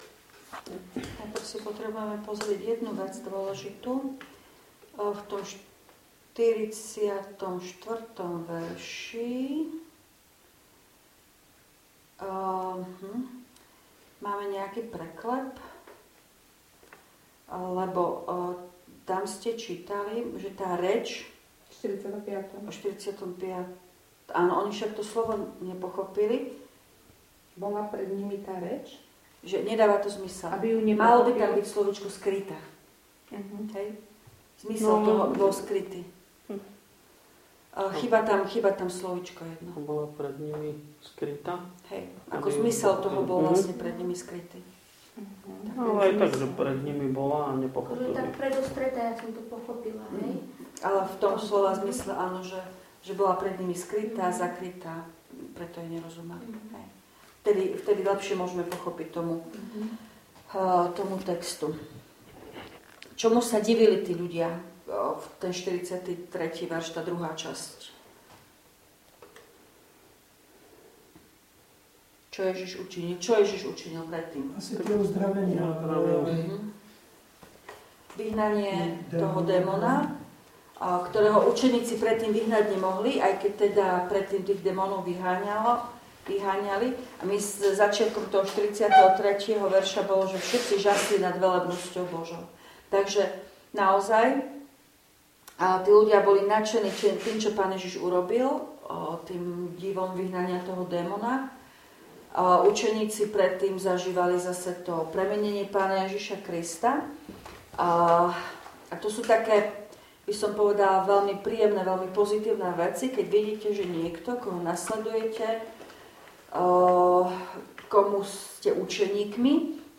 Biblická hodina zo dňa 30.9.2025
V nasledovnom článku si môžete vypočuť zvukový záznam z biblickej hodiny zo dňa 30.9.2025.